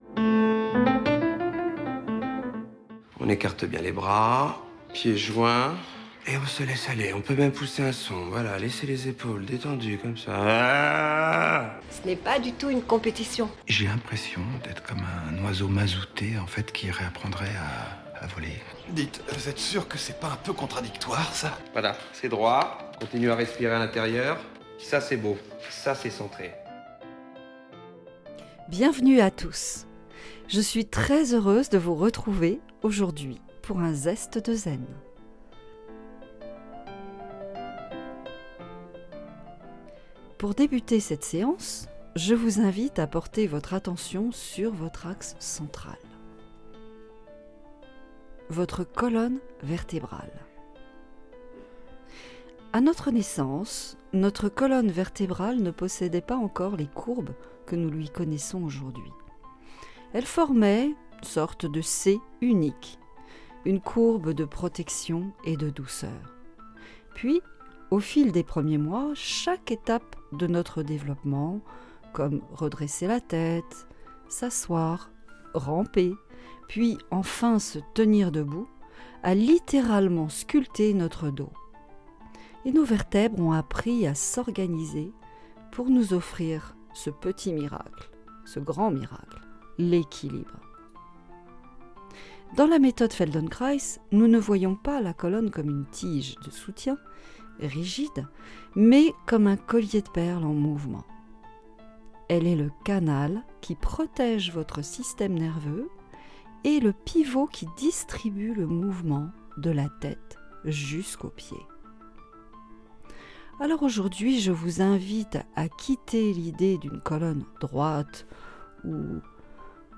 Laissez-vous guider, sans effort et profitez de l'instant et de cette découverte de votre corps, de vos postures et de vos gestes. Pour bien tenir la saison froide dans la durée, on prête attention à sa colonne vertébrale.